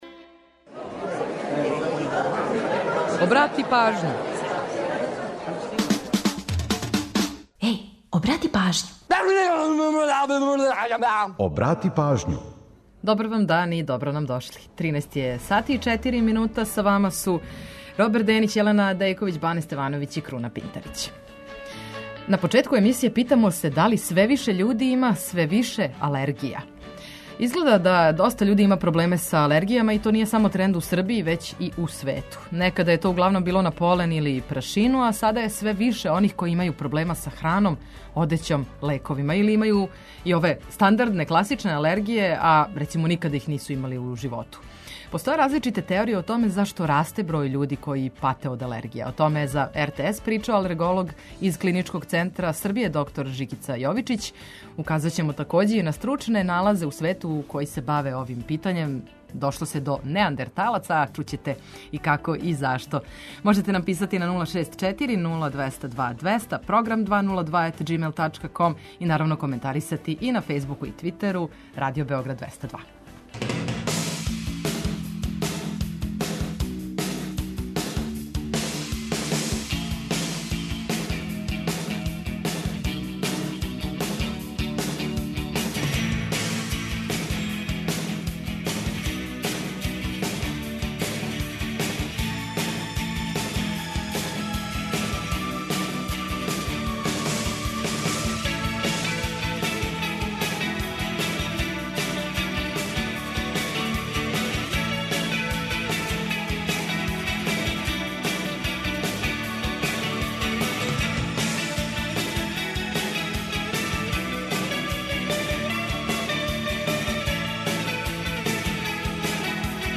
Чућете и „Приче о песмама”, пола сата музике из Србије и региона, а наш репортер упозорава на евентуалне саобраћајне гужве на градским улицама.